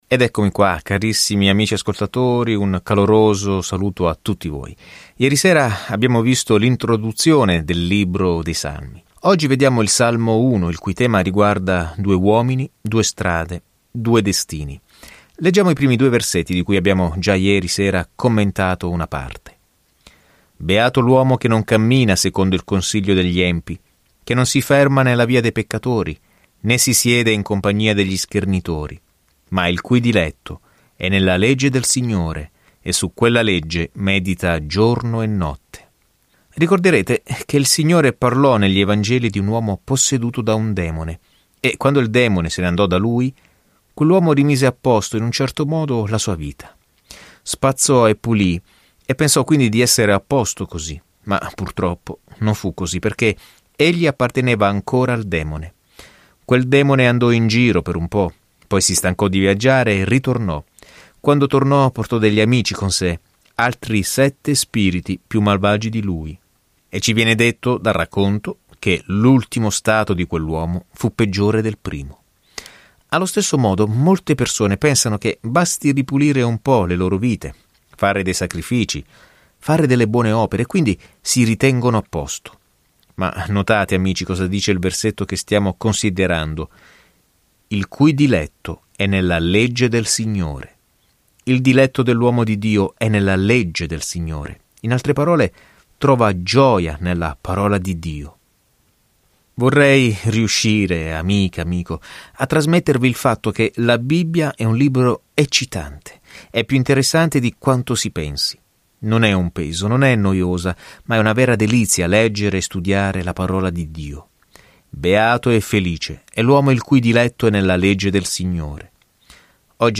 Scrittura Salmi 1:1-6 Giorno 1 Inizia questo Piano Giorno 3 Riguardo questo Piano I Salmi ci danno i pensieri e i sentimenti di una serie di esperienze con Dio; probabilmente ognuno originariamente messo in musica. Viaggia ogni giorno attraverso i Salmi mentre ascolti lo studio audio e leggi versetti selezionati della parola di Dio.